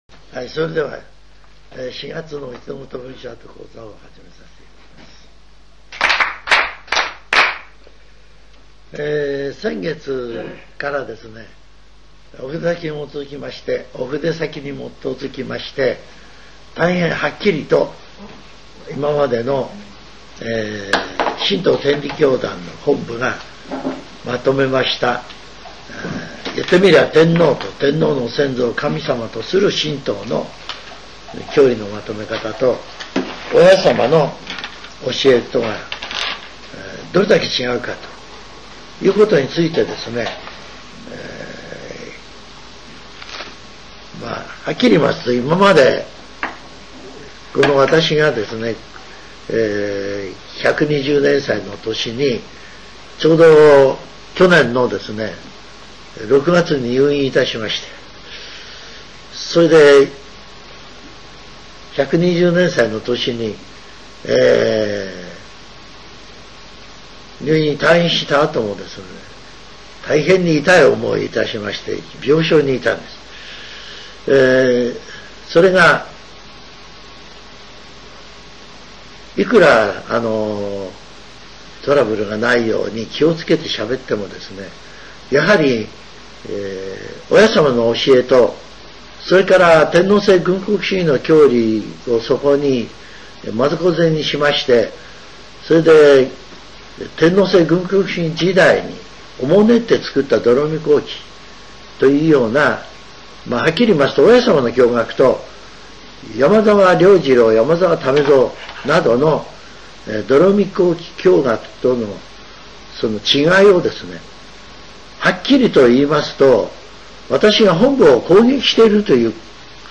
全70曲中59曲目 ジャンル: Speech